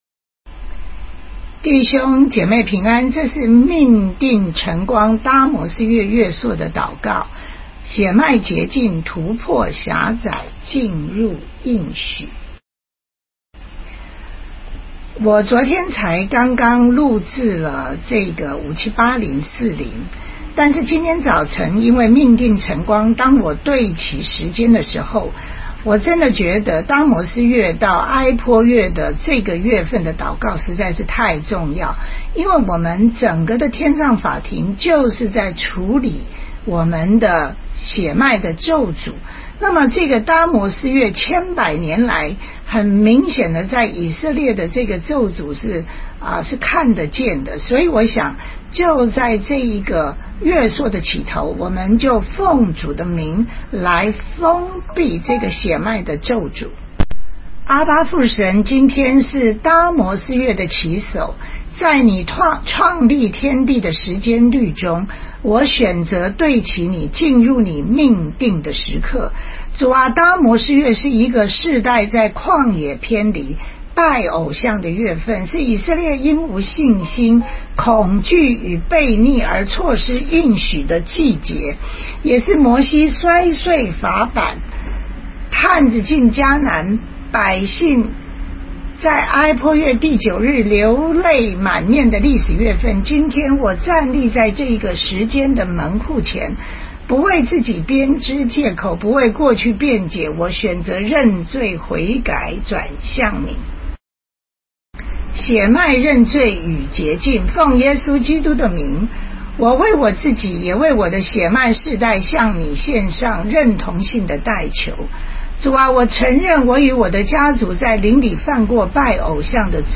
搭模斯月月朔祷告